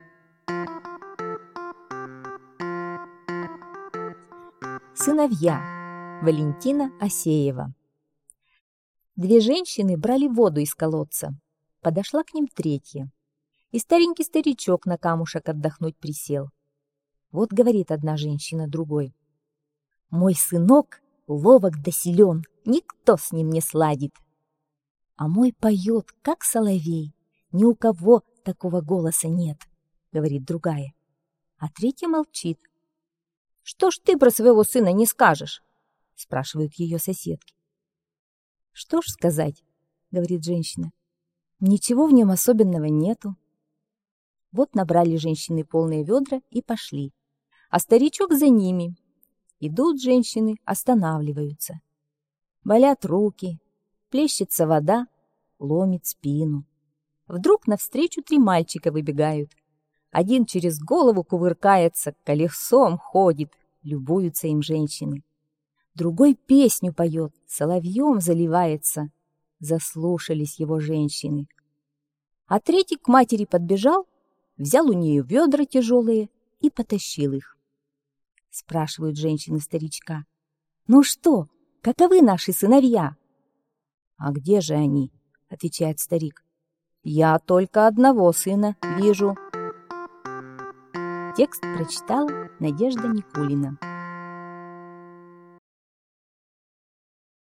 Сыновья - аудио рассказ Осеевой В.А. Рассказ про то, что настоящие любящие сыновья должны беречь свою маму и помогать ей.